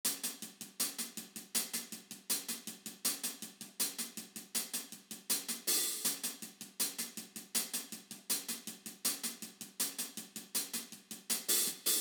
TC3LiveHihatLoop2.wav